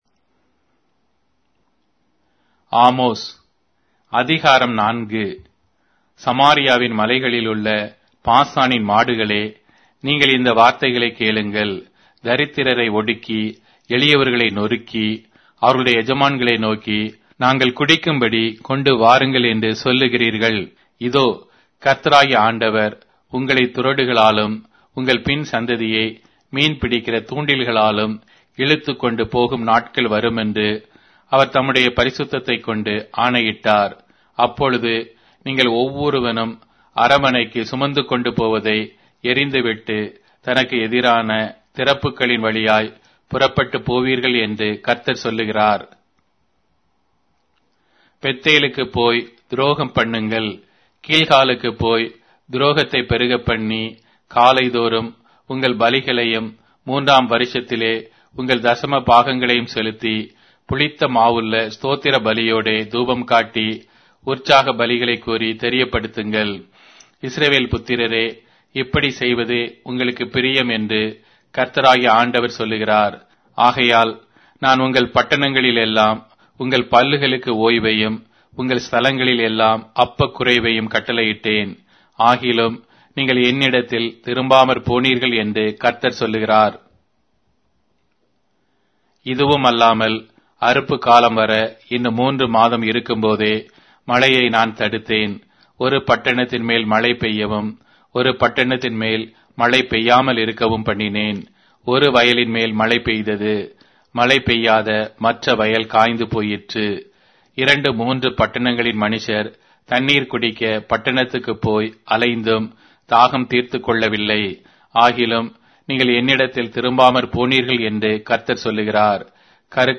Tamil Audio Bible - Amos 3 in Ocvhi bible version